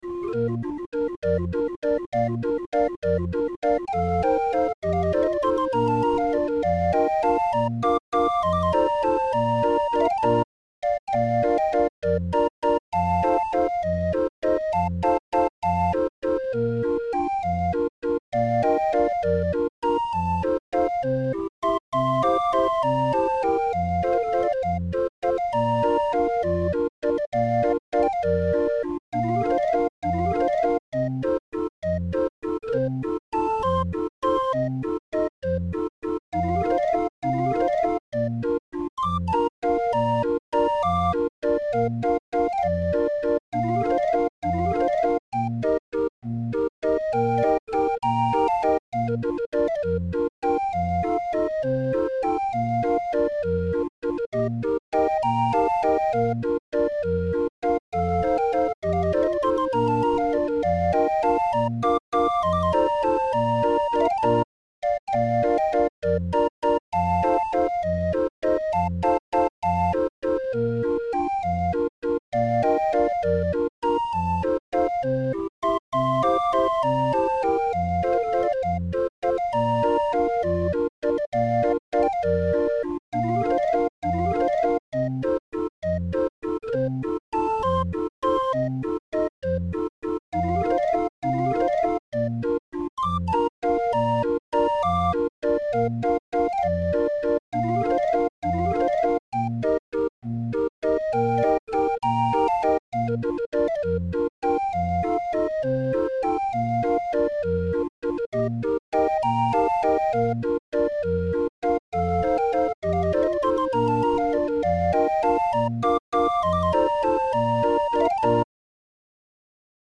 Musikrolle 20-er